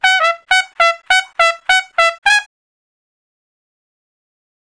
087 Trump Straight (Db) 09.wav